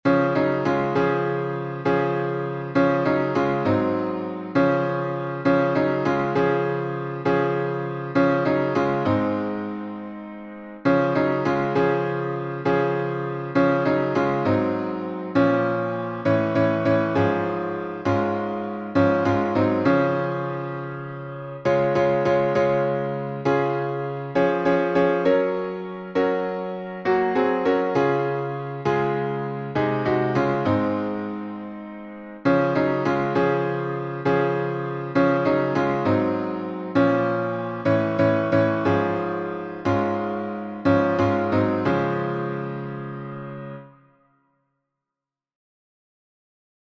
I Must Tell Jesus — D flat major.